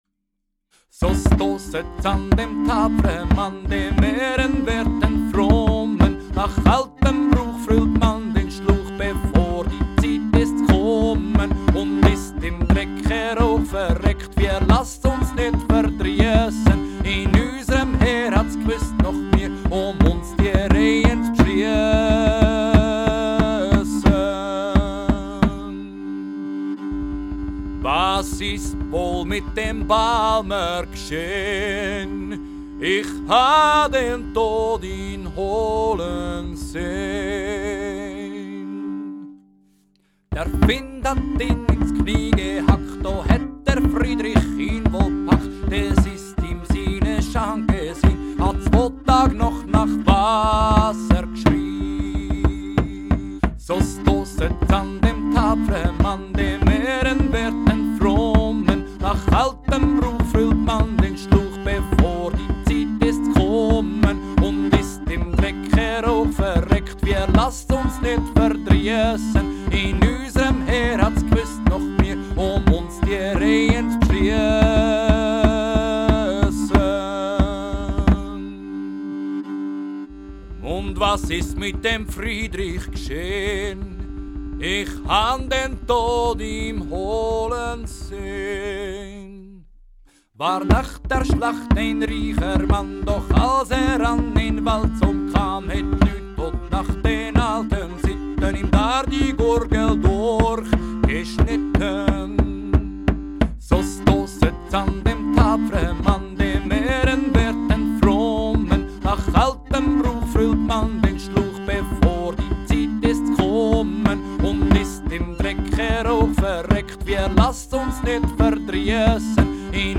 Reisläuferlieder aus dem Spätmittelalter